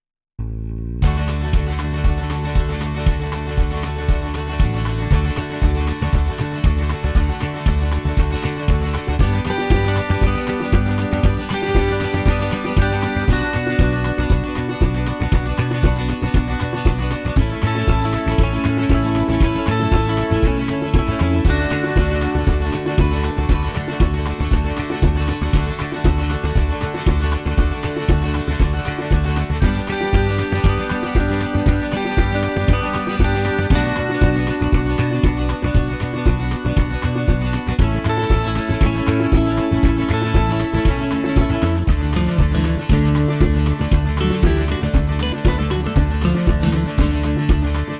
Unique Backing Tracks
key - D - vocal range - (optional A low note) D to D